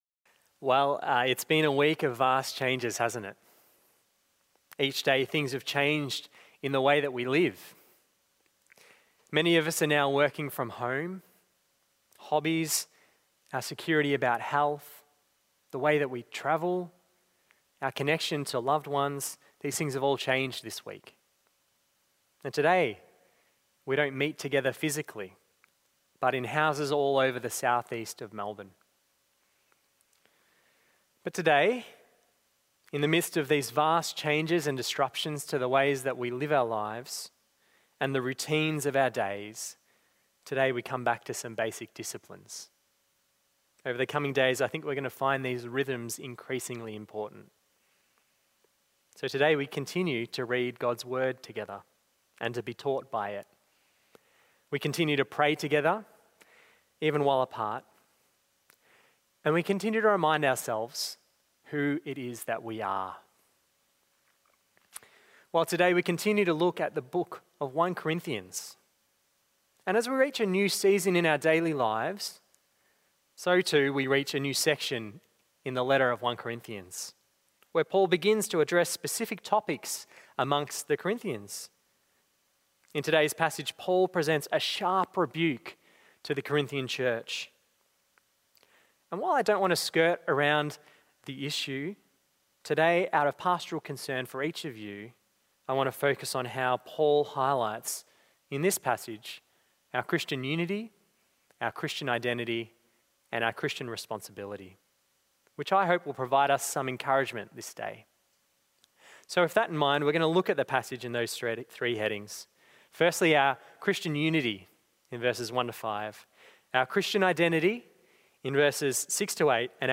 Bible Text: 1 Corinthians 5:1-13 | Preacher